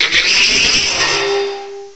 cry_not_kartana.aif